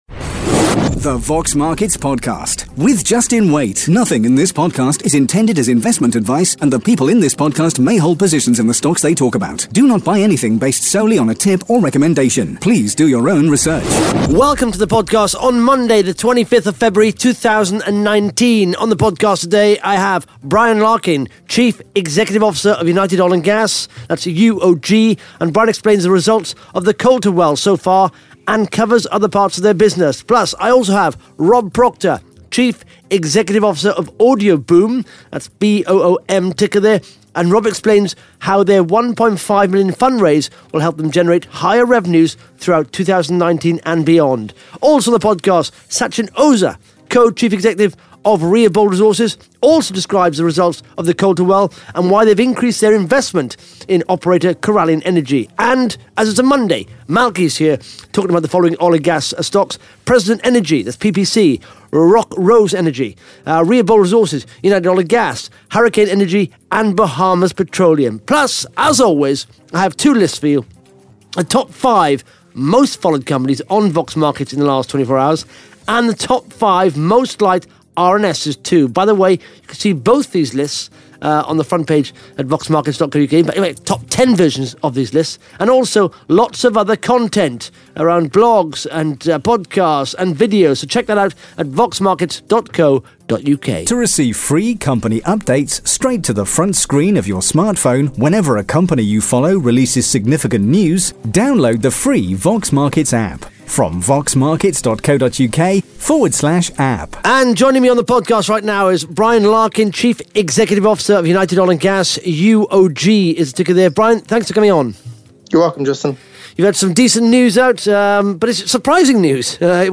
(Interview starts at 14 minutes 24 seconds)